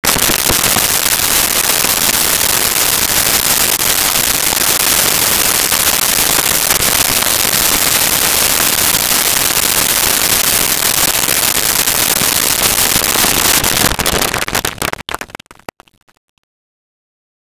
Board Room Applause 02
Board Room Applause 02.wav